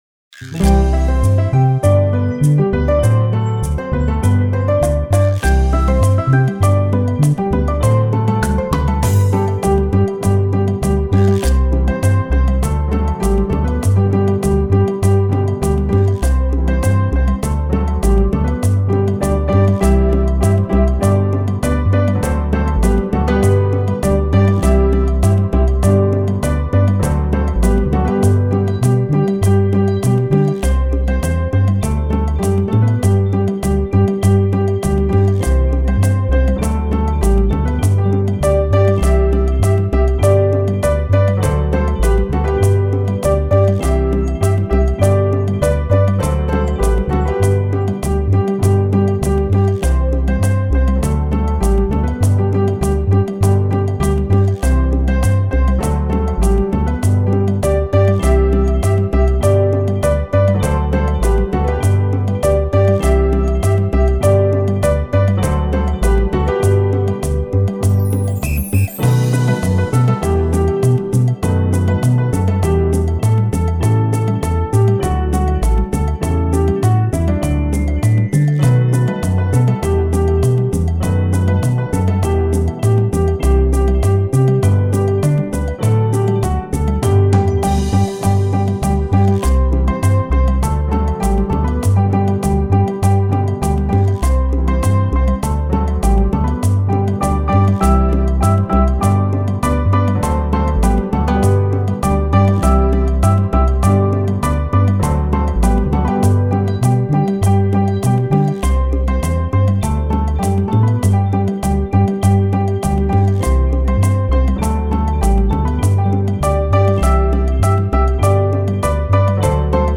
Christmas song, Venezuela
Christmas Karaoke